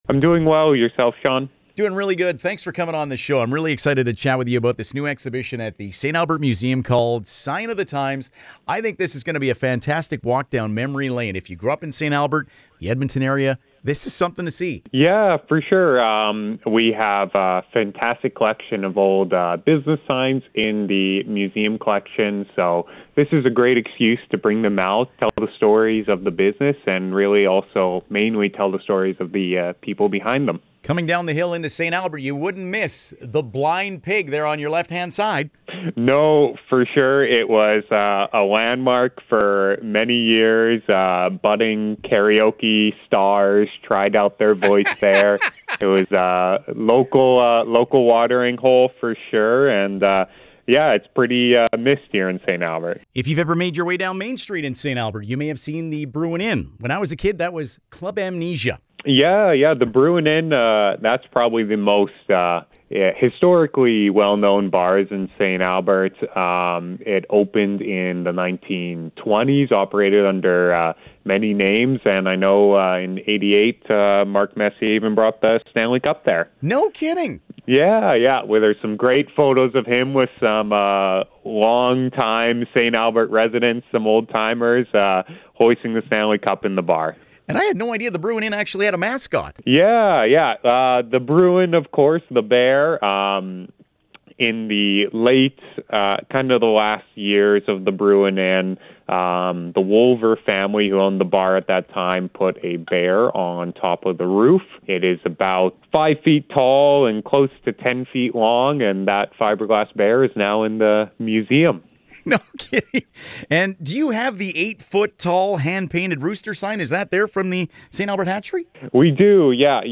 sign-of-the-times-int.mp3